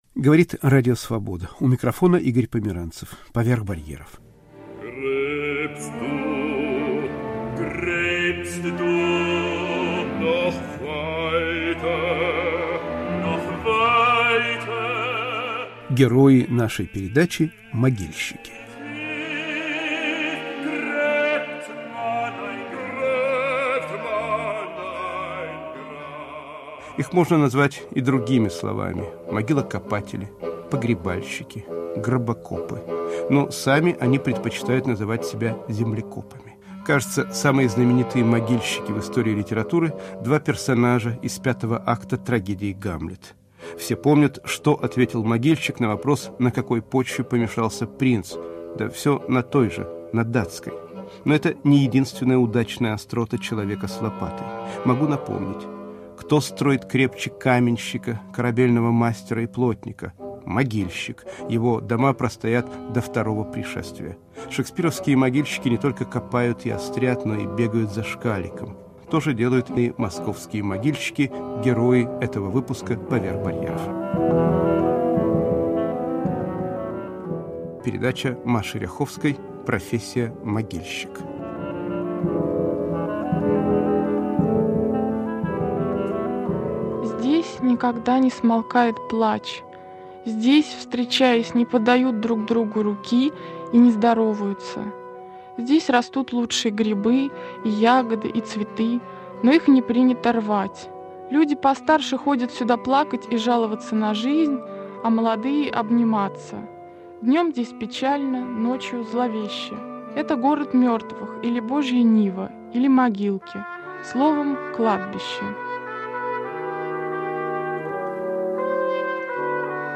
Откровенный разговор с кладбищенскими землекопами